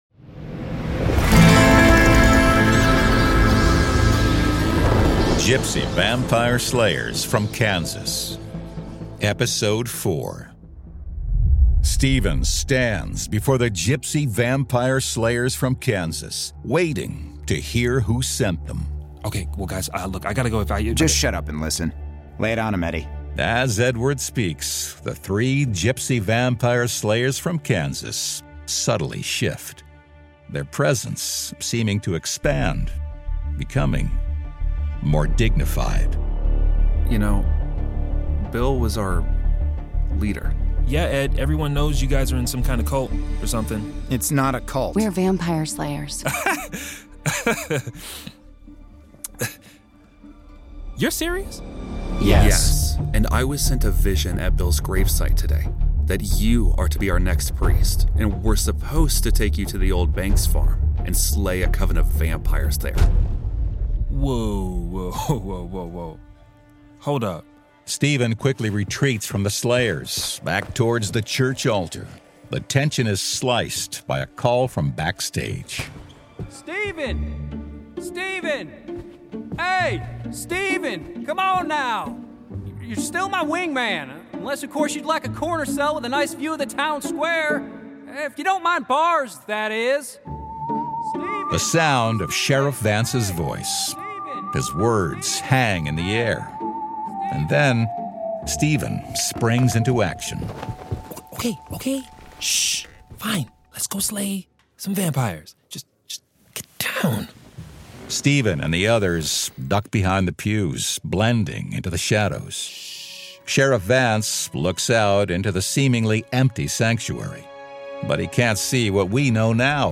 Format: Audio Drama
Voices: Full cast
Narrator: Third Person
Soundscape: Sound effects & music